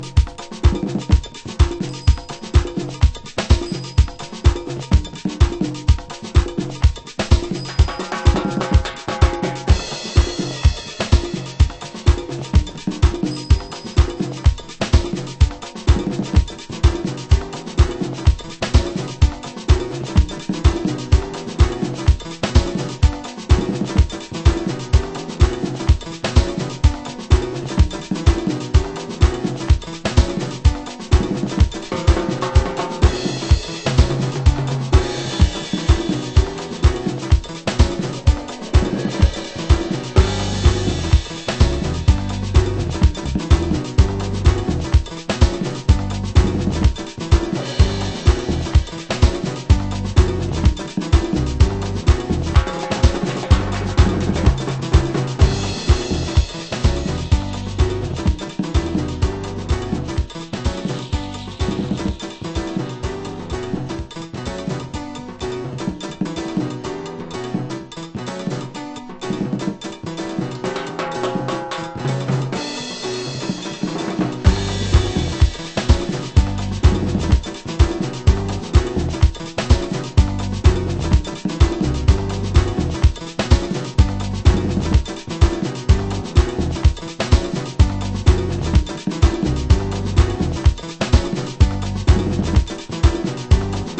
盤質：少しチリパチノイズ有/ラベルに少しシミ汚れ有